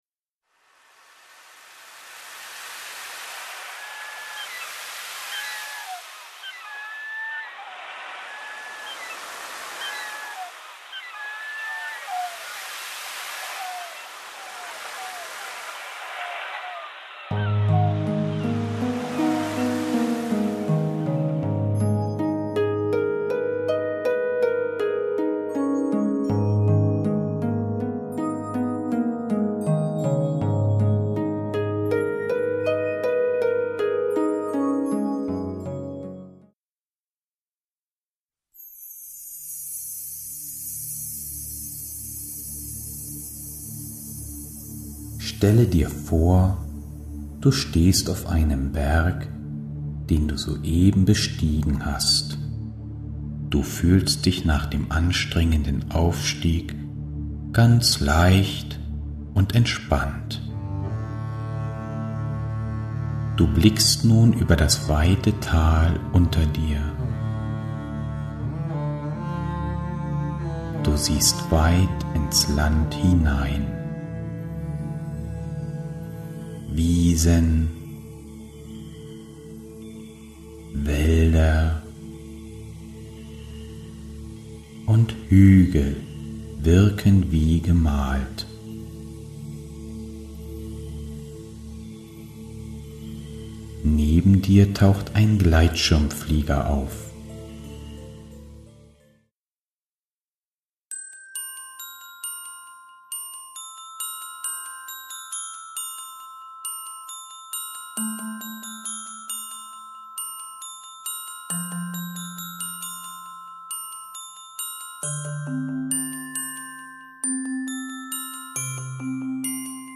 9 instrumentale Stille- und Konzentrationsübungen